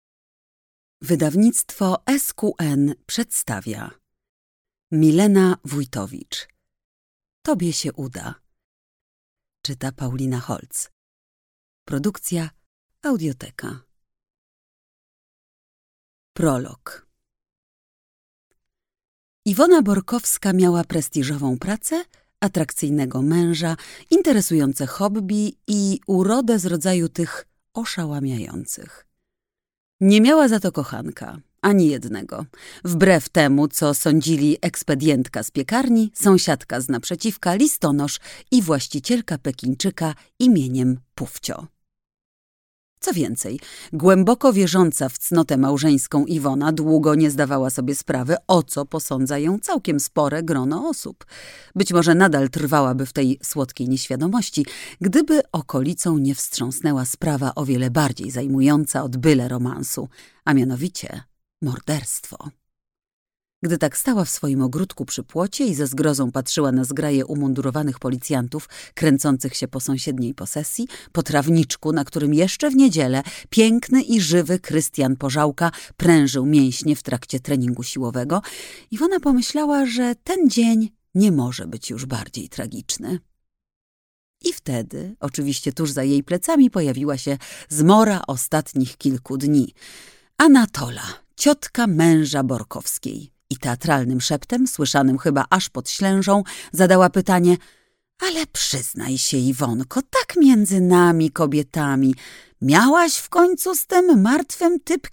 Kup audiobook: Tobie się uda Autor: Milena Wójtowicz Kategoria: SQN POP, kryminał/thriller, komedia kryminalna, powieść.
Autor książki: Milena Wójtowicz Lektor: Paulina Holtz Słuchaj w aplikacji PulpUP (instrukcja)